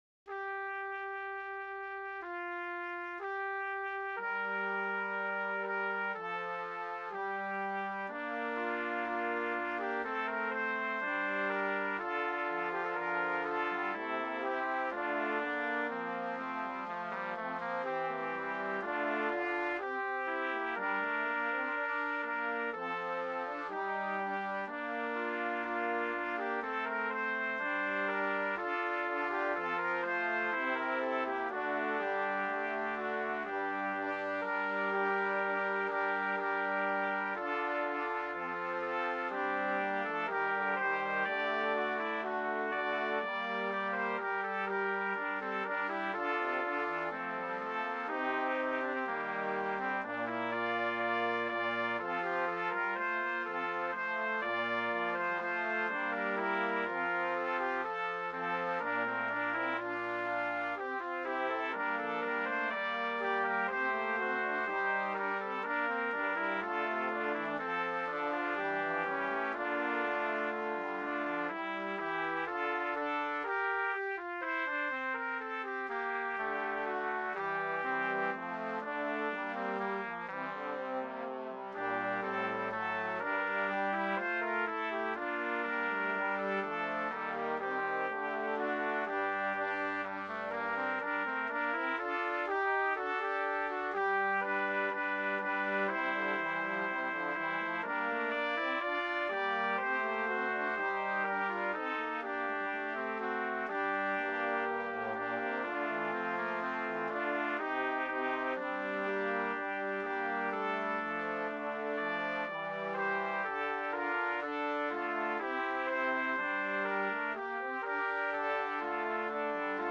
BRASS QUARTET
FOR 2 TRUMPETS, 2 TROMBONES